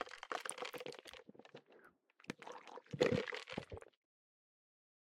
ASMR – Drinking Straw, Iced coffee
With rattling cubes and a straw sucking on the last bits of drink, you have something to make your iced-drink shot come to life.
All-You-Can-Eat-Audio-ASMR-Drinking-Straw-Iced-coffee.mp3